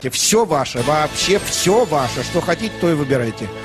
Голос Леонида Якубовича звучит узнаваемо